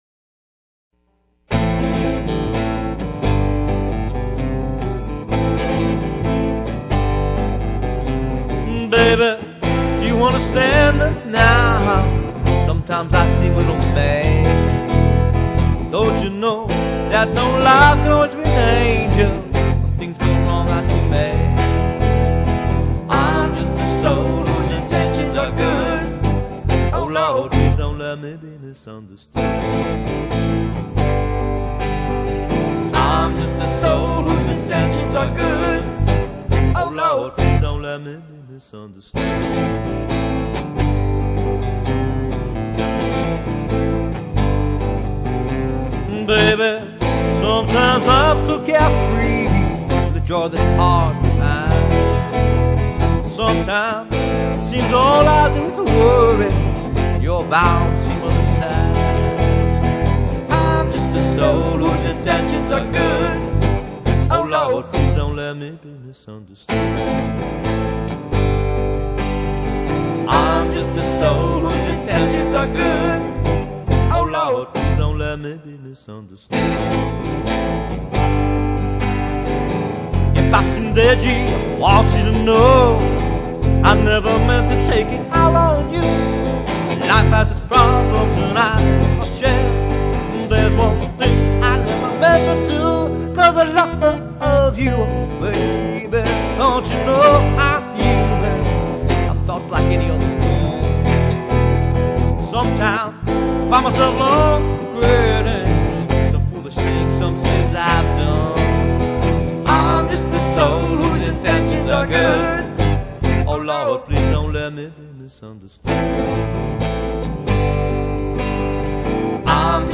lead guitar
vocals, harmonica, guitar
vocals, guitar, percussion
bass guitar
unplugged classic rock and blues band